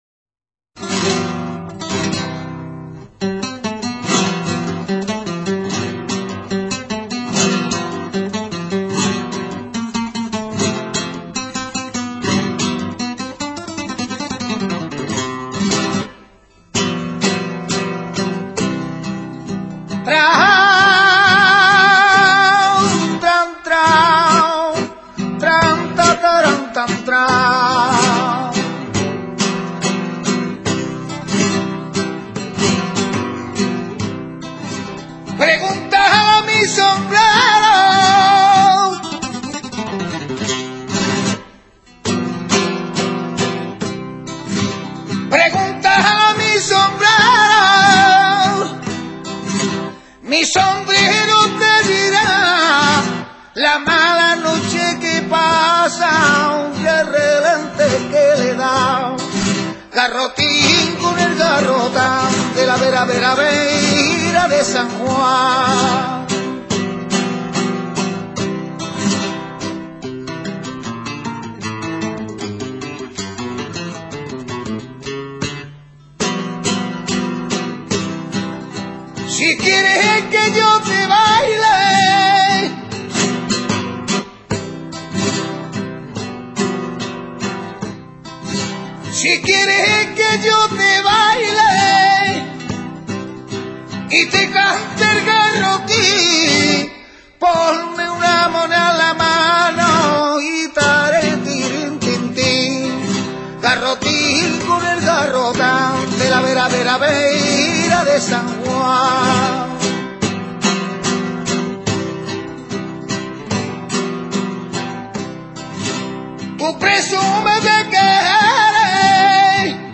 Es de ritmo vivo y dio nombre al cante que lo acompa�a. // 2.
Es cante de tipo festero, con ritmo de tango y un tanto artificioso, con letras sencillamente graciosas y muy simples en su contenido.
garrotin.mp3